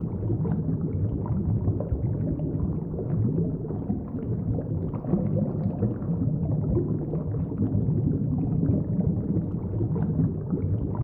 ENEMY_AQUEOS_LOOP.ogg